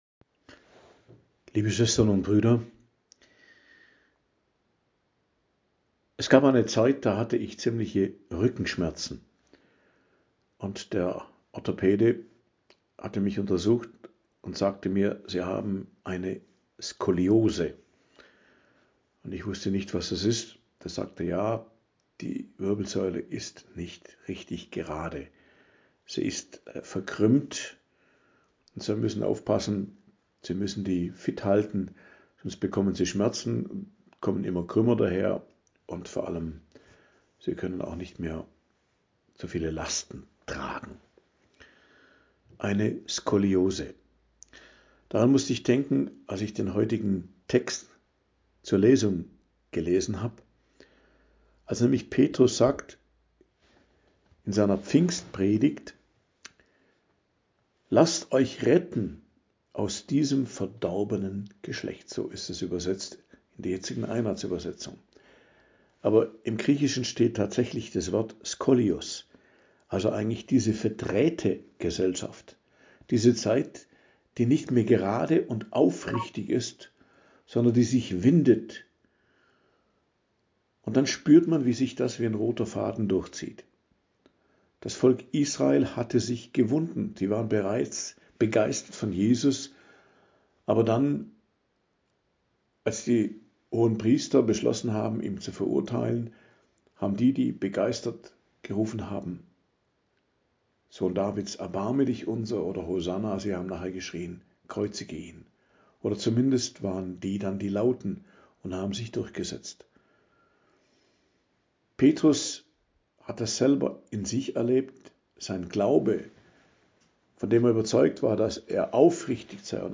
Predigt am Dienstag der Osteroktav, 22.04.2025